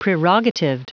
Prononciation du mot prerogatived en anglais (fichier audio)